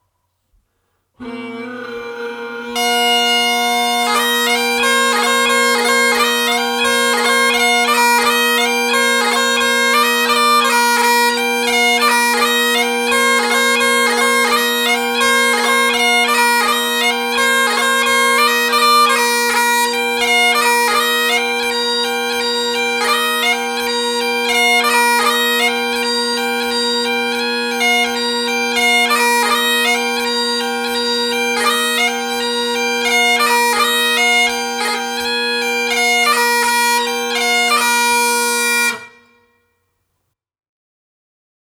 Halifax Bagpiper